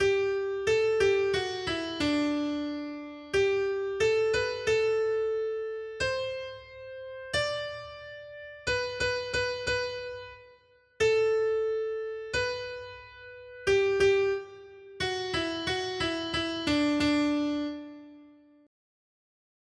Noty Štítky, zpěvníky ol292.pdf responsoriální žalm Žaltář (Olejník) 292 Skrýt akordy R: Ty, Hospodine, vládneš nade vším. 1.